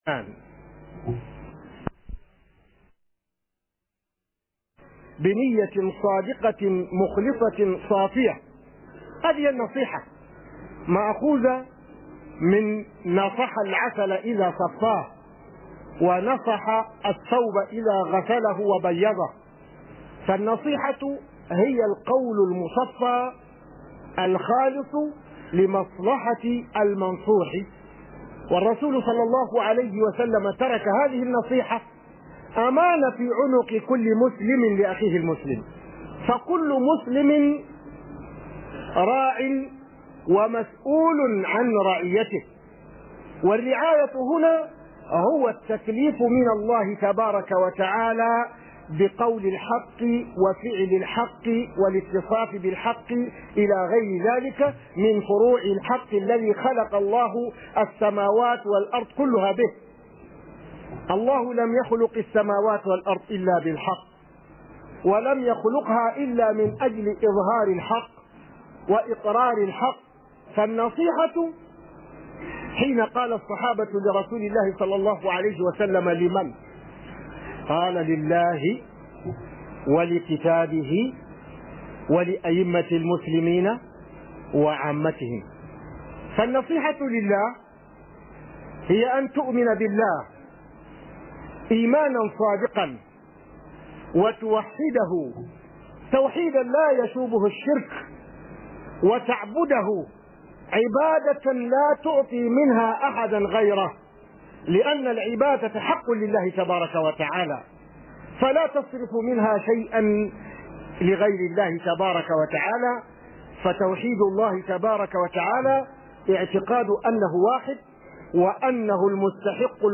KHUDBAH JUMA-A
014 KHUDBAH SHARIF IBRAHIM.mp3